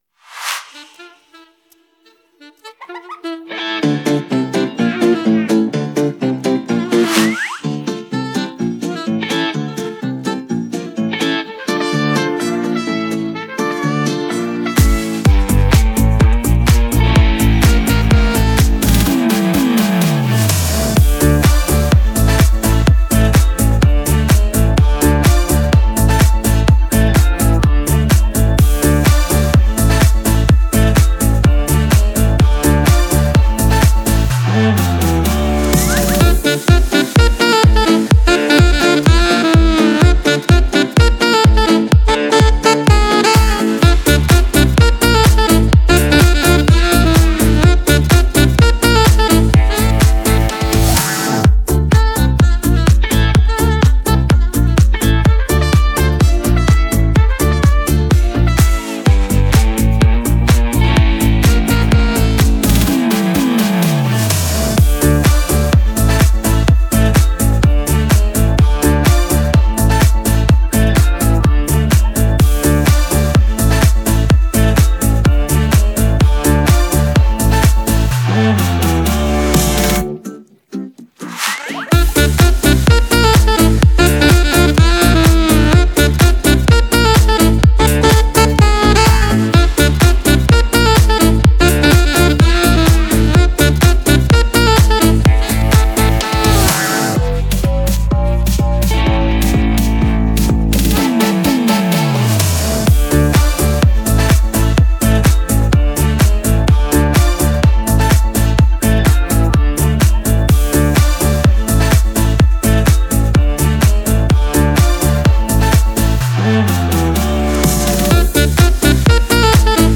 Детские песни